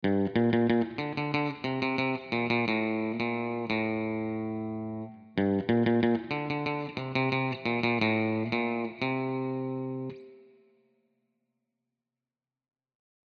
chiqui-audio---strato-wet-melo.mp3